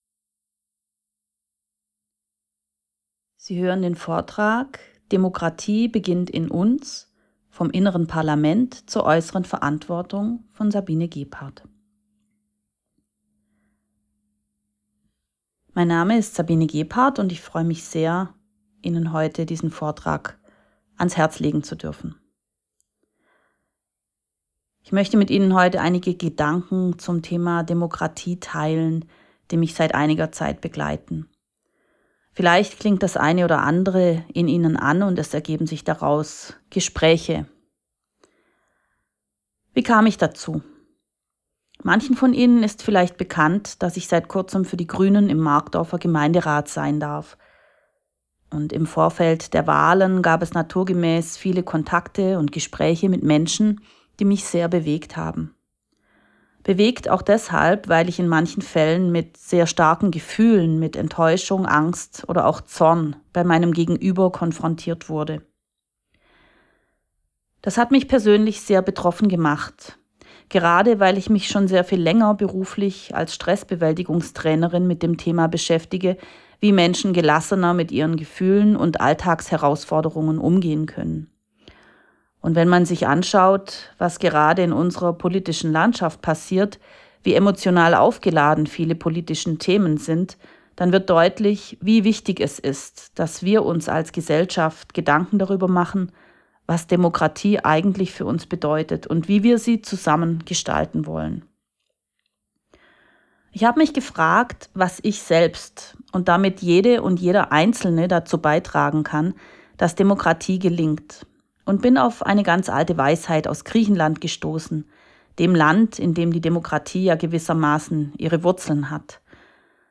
lange_nacht_der_demokratie_markdorf_2024